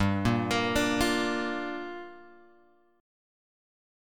Gm7 chord {3 5 3 3 6 3} chord